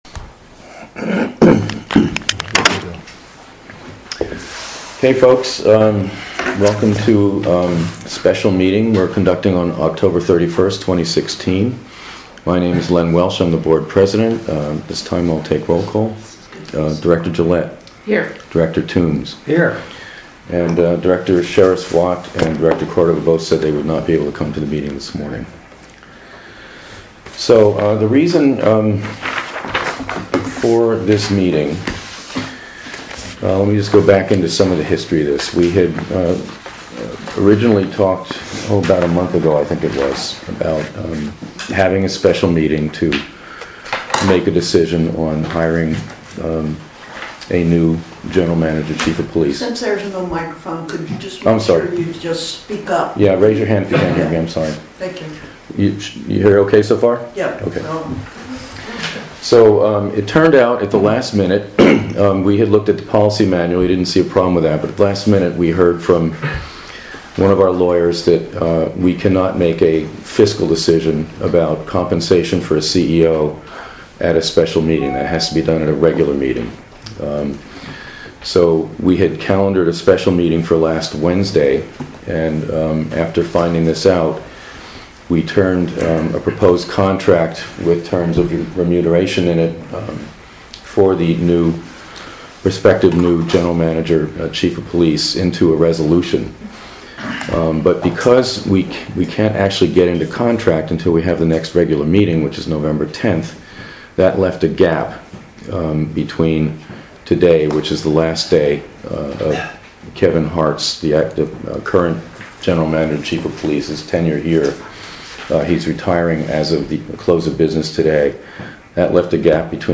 KCSD Board Meeting
Board meetings are held once monthly, on the second Thursday of the month.